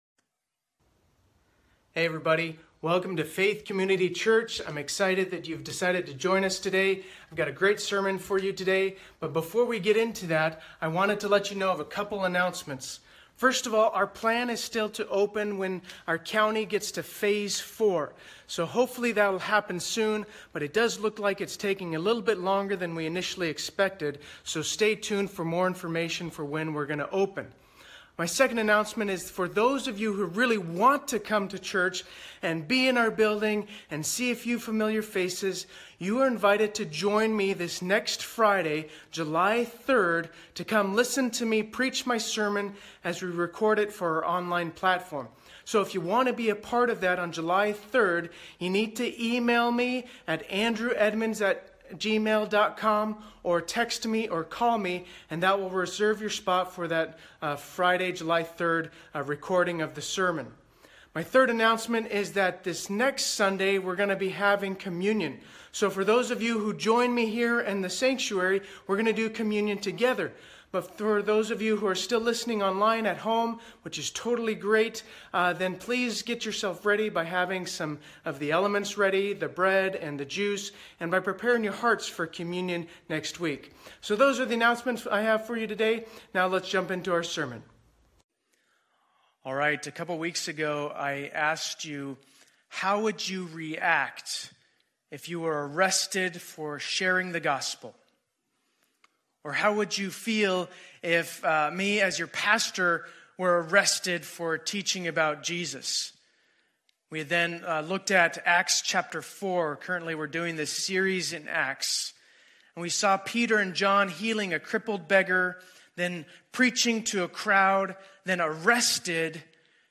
2020-06-28 Sunday Service
Opening, Teaching, and Lord’s Prayer